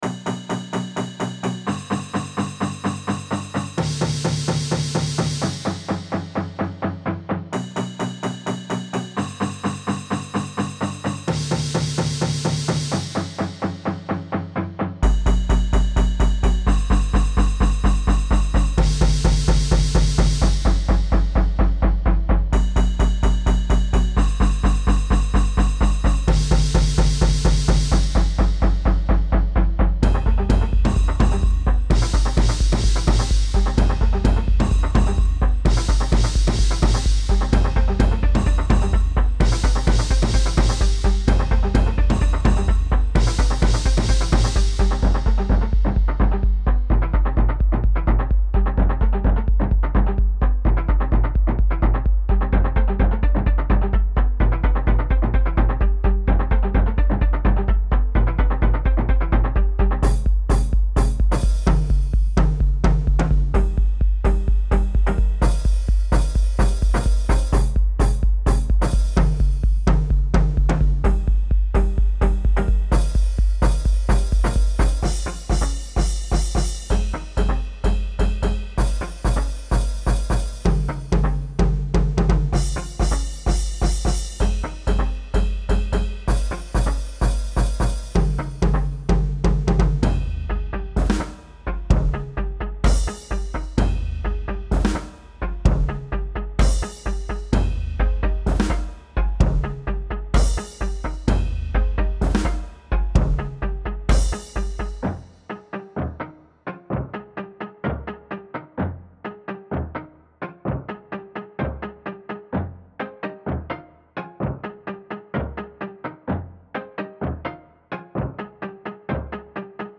Home > Music > Electronic > Bright > Running > Chasing